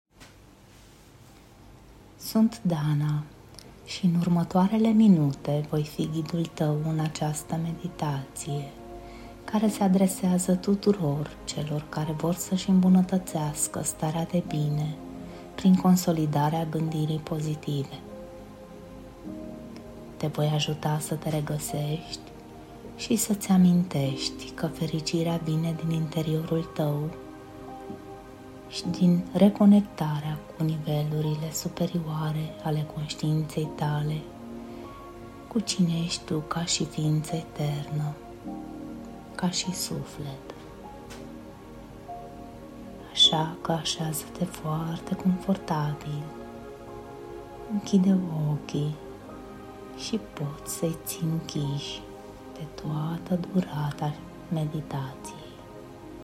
Meditatie puternica pentru consolidarea egoului pozitiv
Această meditație ghidată este concepută pentru a-ți întări încrederea în sine, a-ți clarifica scopurile și a te reconecta cu puterea ta interioară. Prin tehnici de relaxare profundă și vizualizare pozitivă, vei învăța să îți consolidezi un ego sănătos și echilibrat, care te susține în depășirea provocărilor și în manifestarea potențialului tău maxim.